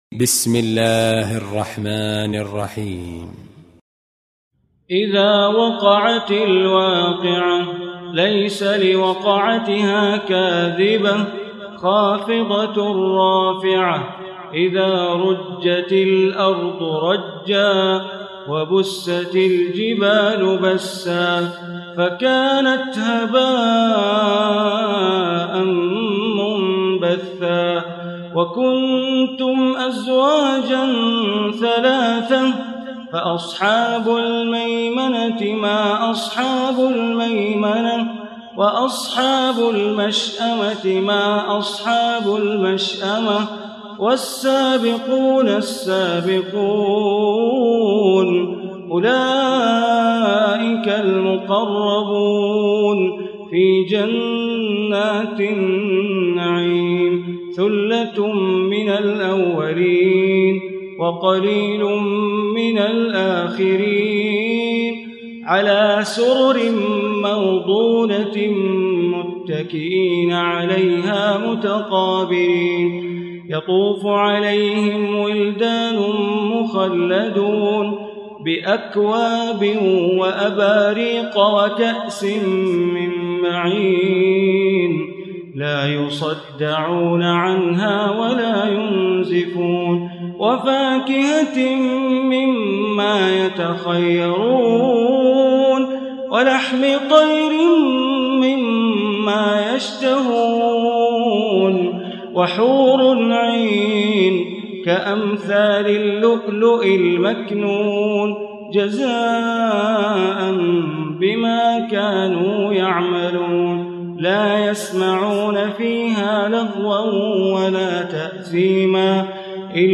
Surah Waqiah Recitation by Sheikh Bandar Baleela
Surah Waqiah, listen online mp3 tilawat / recitation in the voice of Imam e Kaaba Sheikh Bandar Baleela.